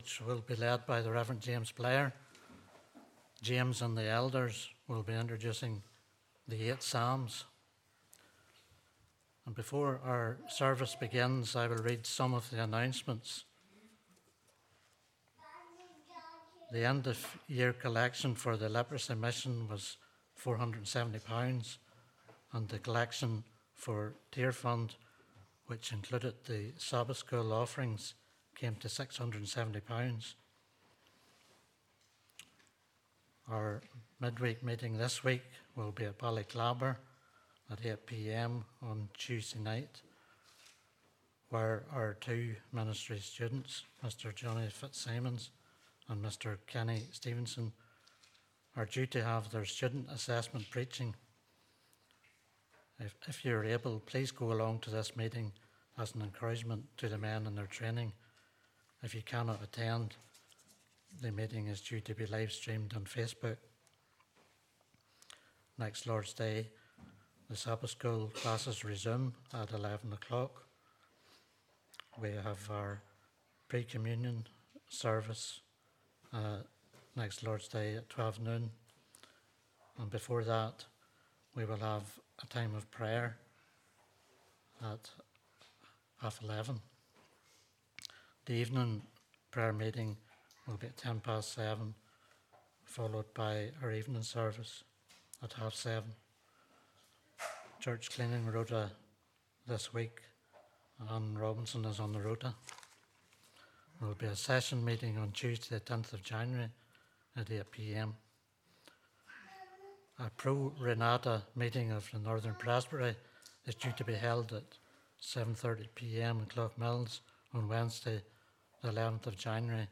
Praise Service Jan 2023